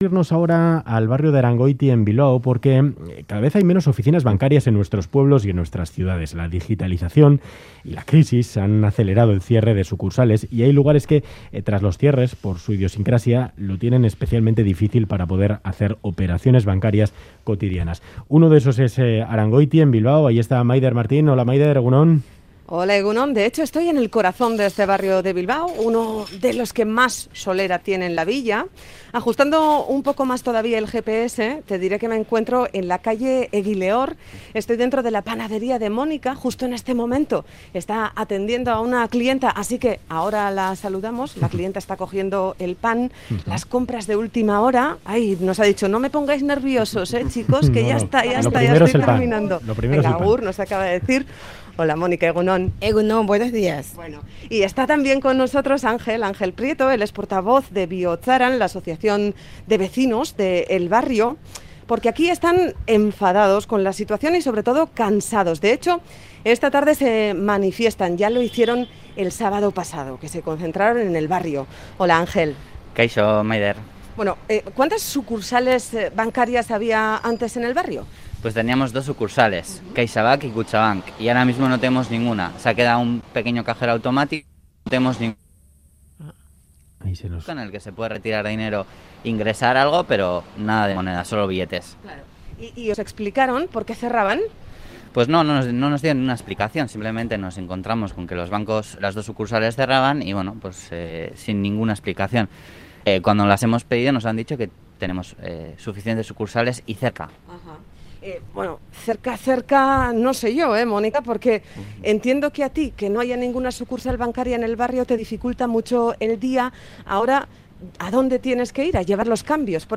Audio: Visitamos el barrio de Arangoiti de Bilbao para ver cómo afecta el cierre de sucursales bancarias a la vida diaria del barrio.